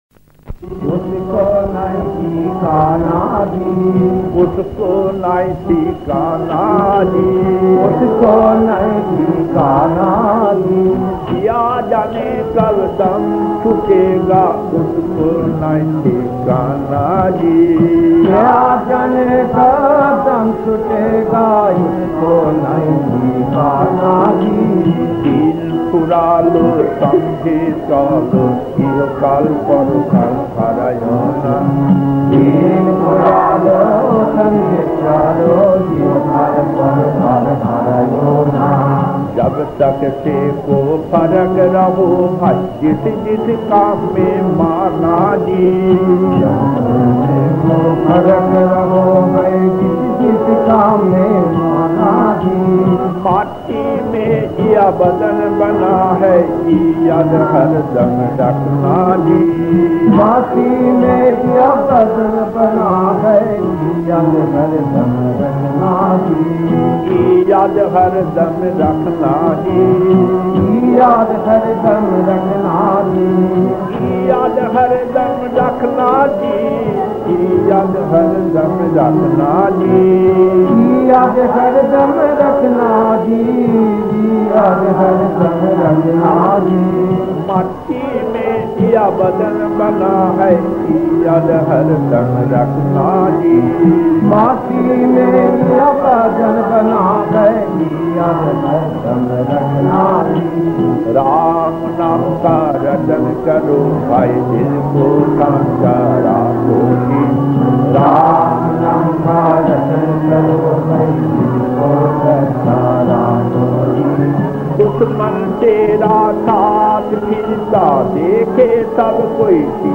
Kirtan